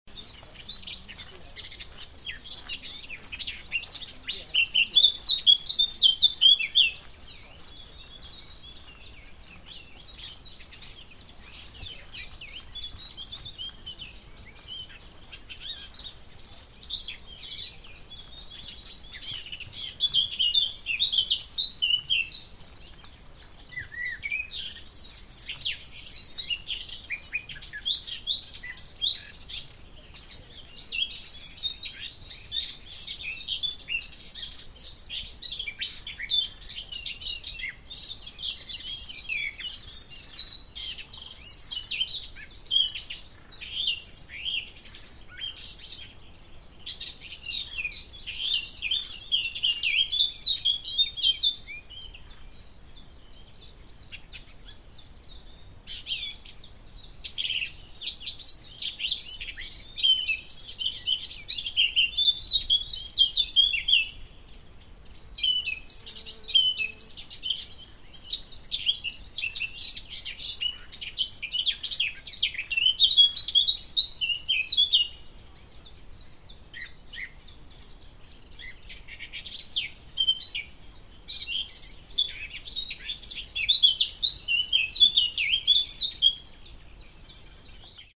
South Somerset RSPB group organised a walk round Ham Wall on 17 May 2014.
The sounds (all in stereo, mp3 files):-
Blackcap.
HamWall_Blackcap_STE-024.mp3